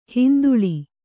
♪ hinduḷi